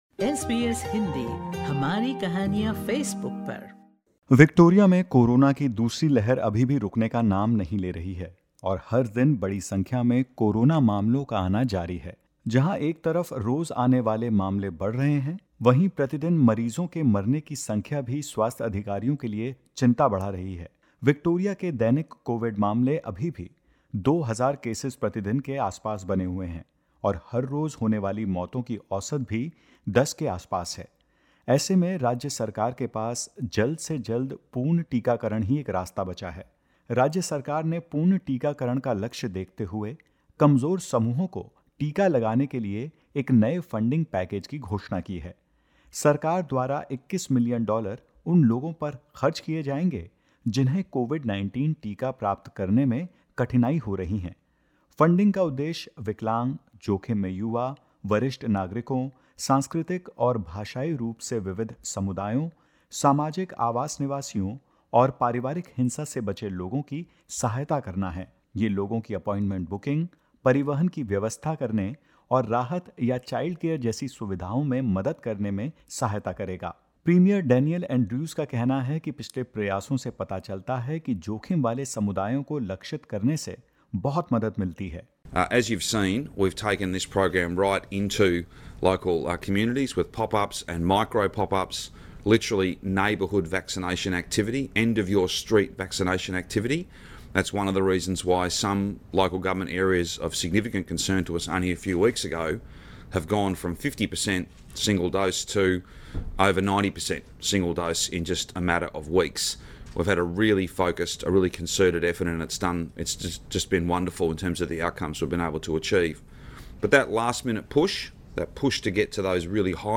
विक्टोरिया में कोरोना की दूसरी लहर अभी भी रूकने का नाम नहीं ले रही है और हर दिन बड़ी संख्या में कोरोना मामलों का आना लगातार जारी है। जहां विक्टोरिया में कोरोना मामले अभी भी नियंत्रण के बाहर हैं वहीं न्यू साउथ वेल्स की स्थिति बेहतर दिख रही है। इस रिपोर्ट में सुनते हैं ऑस्ट्रेलिया में कोविड की दूसरी लहर के बीच क्या कुछ हो रहा है।